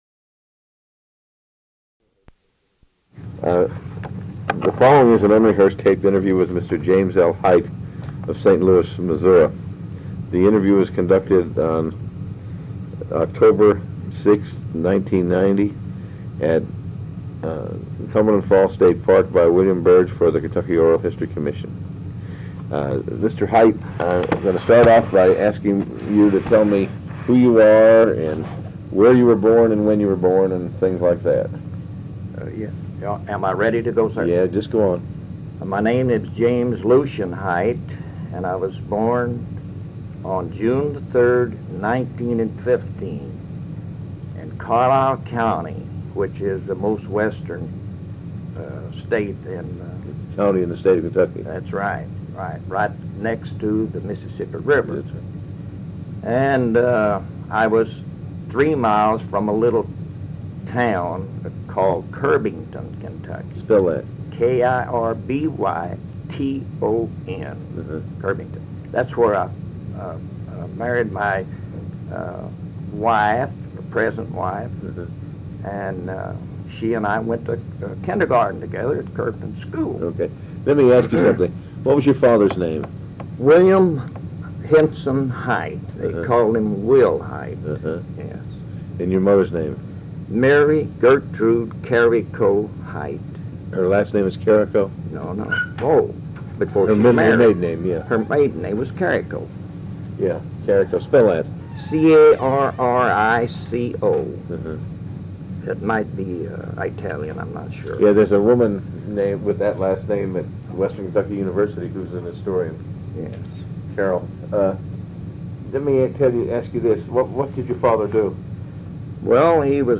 Kentucky Historical Society